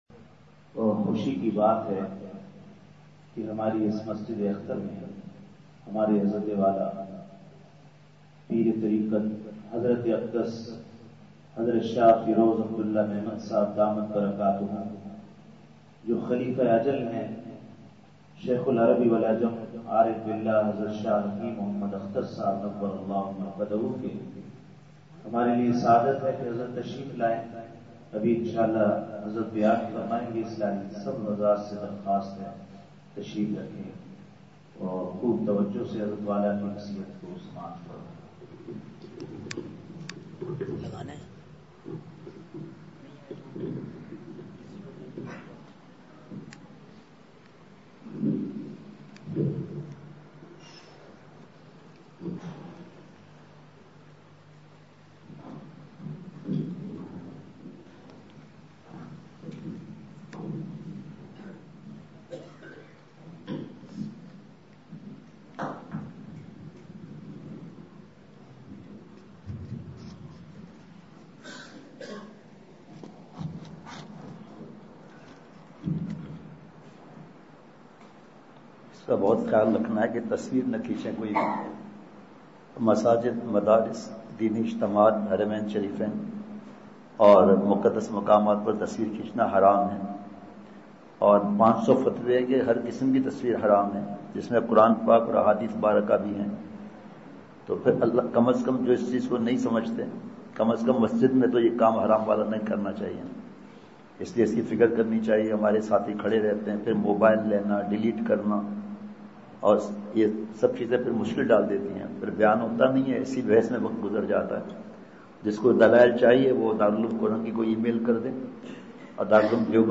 *بمقام:۔مسجدِاخت میاں چنوں*
*نمبر(17):بیان*